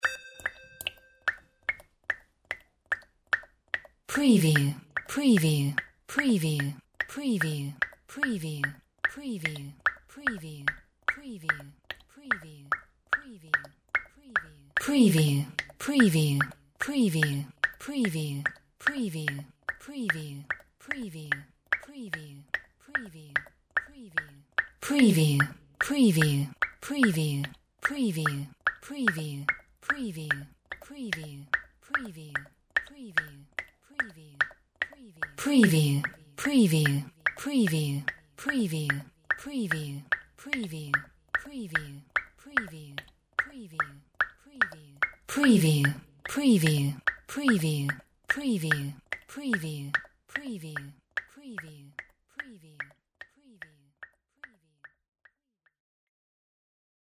Water droplets multiple 01
Stereo sound effect - Wav.16 bit/44.1 KHz and Mp3 128 Kbps
previewWAT_DRIP_MULTIPLE_WBHD01.mp3